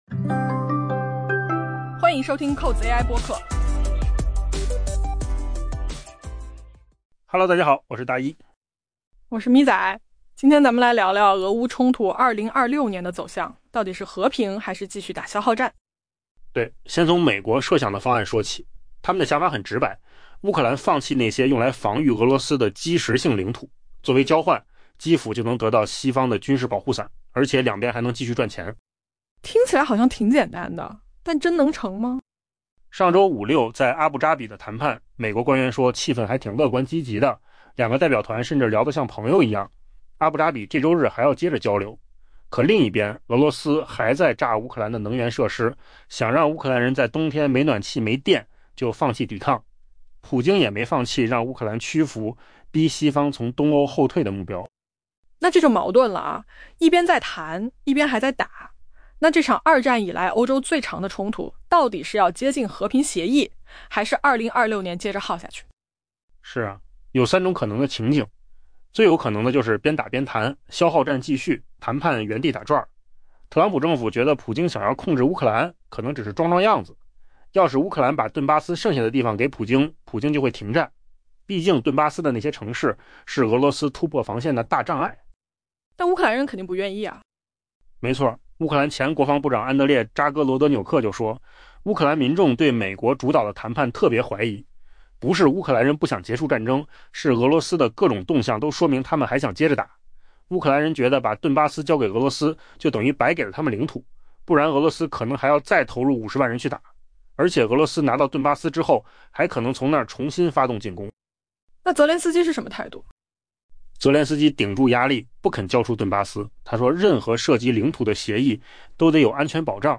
AI播客：换个方式听新闻